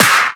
Clap
ED Claps 20.wav